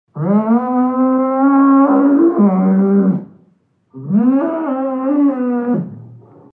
Звуки быка
Звук бычьего мычания в сарае